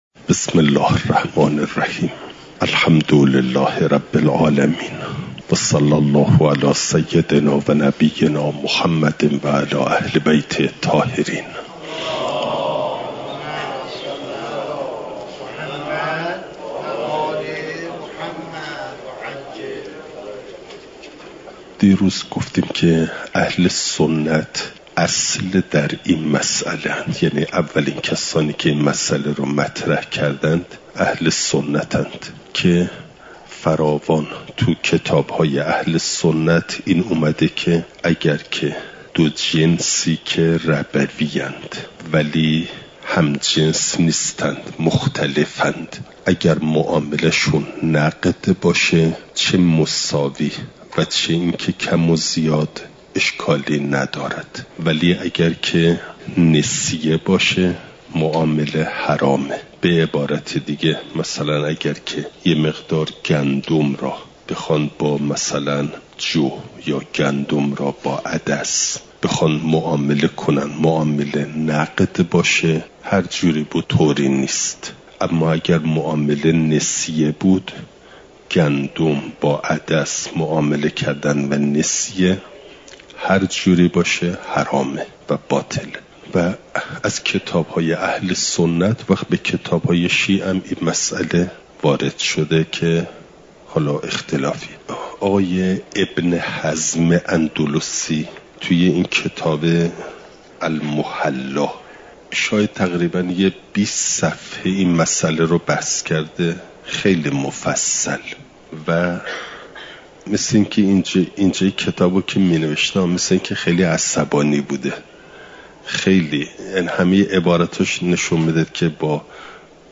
نظام اقتصادی اسلام؛ مبحث ربا (جلسه۴۴) « دروس استاد